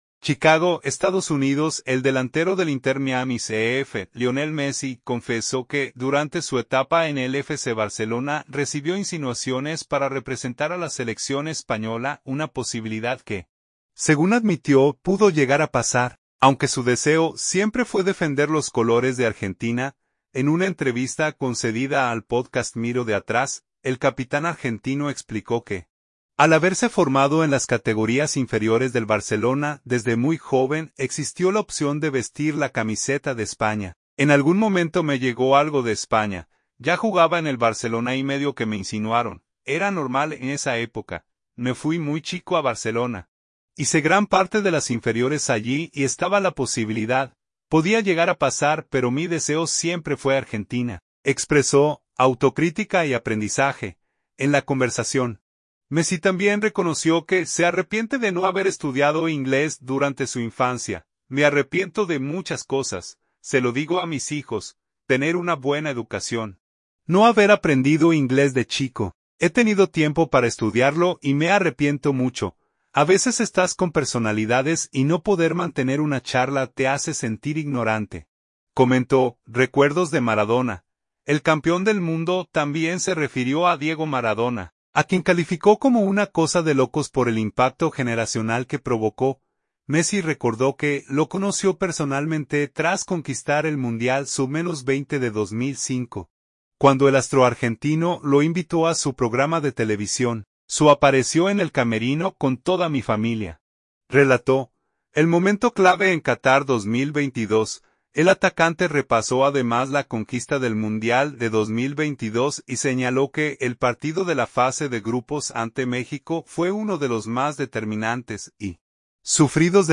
En una entrevista concedida al pódcast Miro de Atrás, el capitán argentino explicó que, al haberse formado en las categorías inferiores del Barcelona desde muy joven, existió la opción de vestir la camiseta de España.